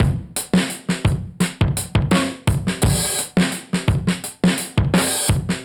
Index of /musicradar/dusty-funk-samples/Beats/85bpm/Alt Sound